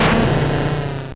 Cri de Granbull dans Pokémon Or et Argent.